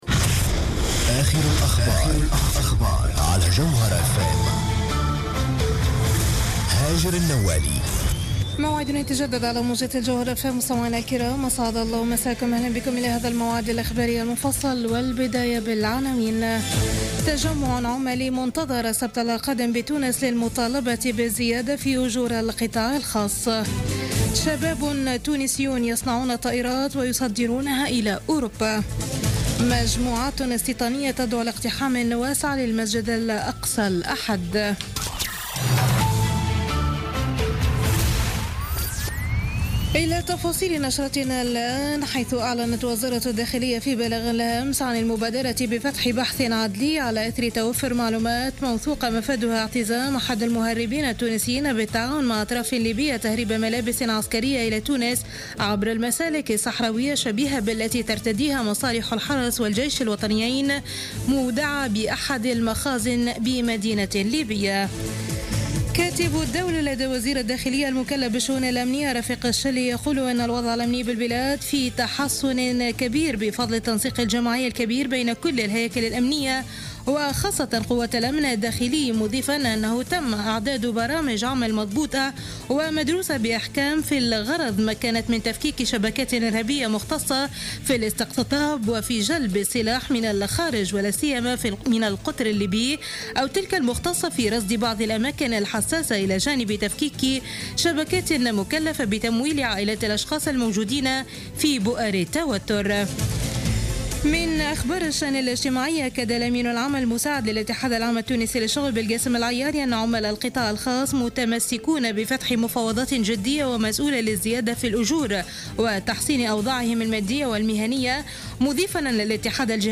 نشرة أخبار منتصف الليل ليوم الأحد 25 أكتوبر 2015